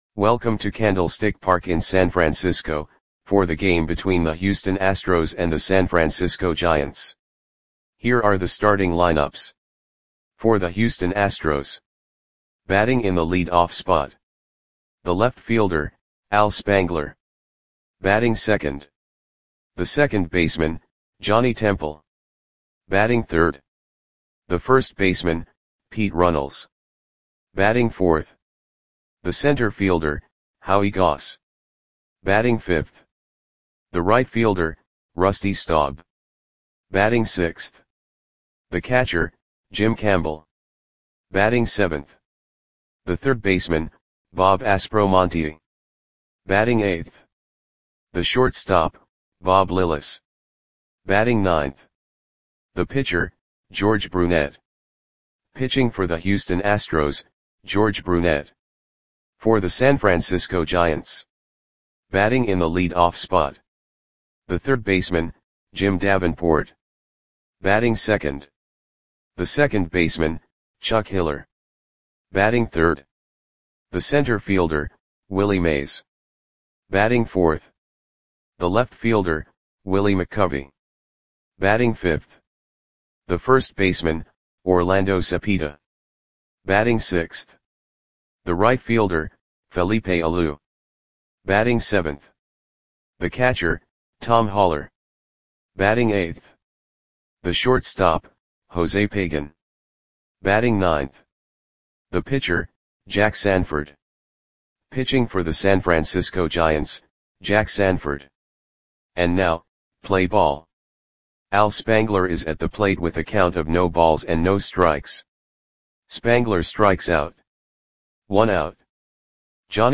Audio Play-by-Play for San Francisco Giants on April 18, 1963
Click the button below to listen to the audio play-by-play.